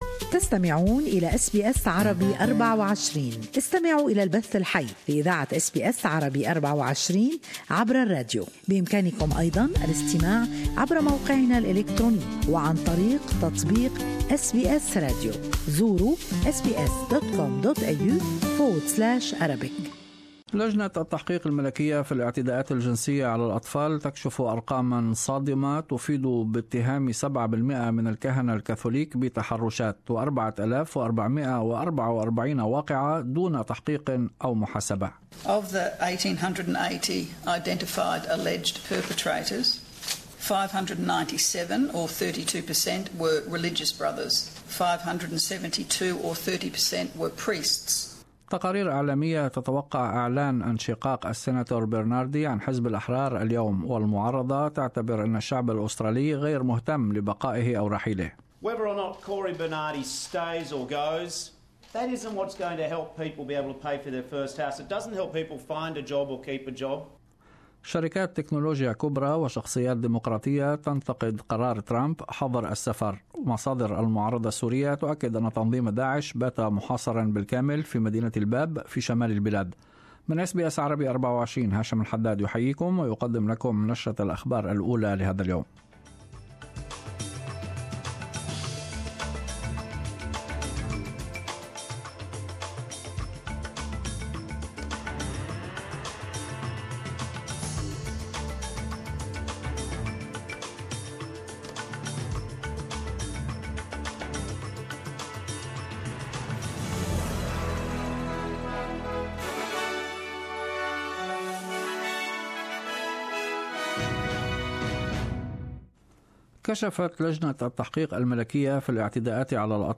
News Bulliten 7-2-17